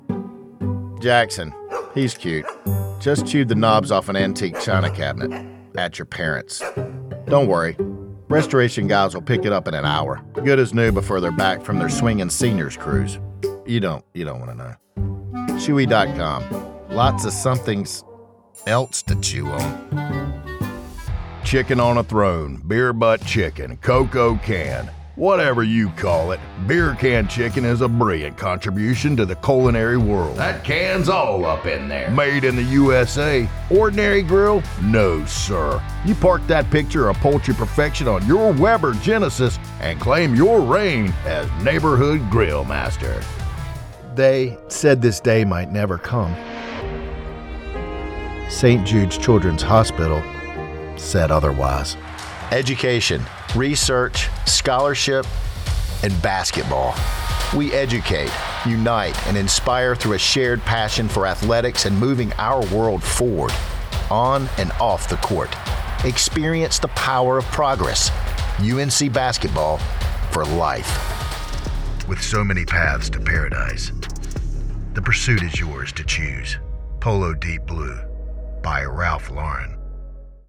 American Voice Over Talent
Adult (30-50) | Older Sound (50+)
Our voice over talent record in their professional studios, so you save money!